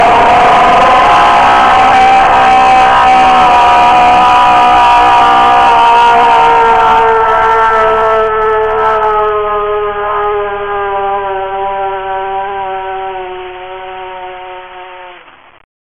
freddy jumpscare
freddy-jumpscare.mp3